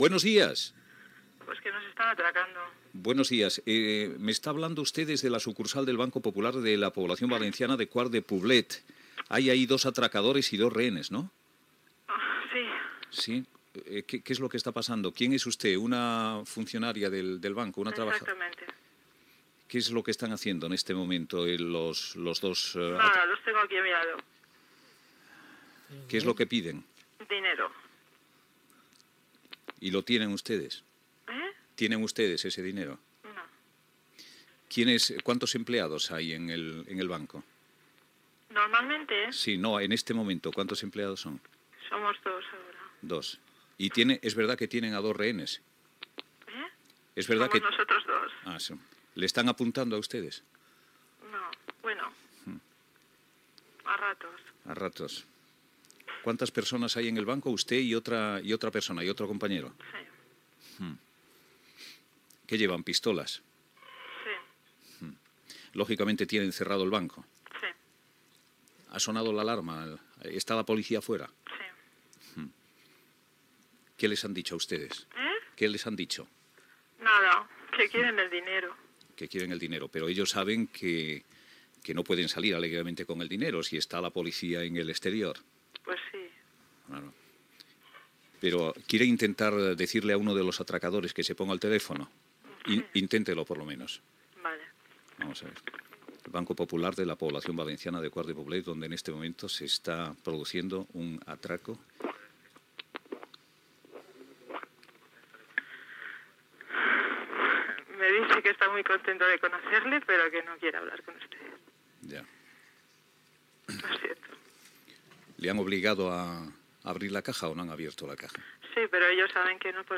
Conversa amb una hostatge d'una sucursal bancària del Banco Popular de Quart de Poblet
Info-entreteniment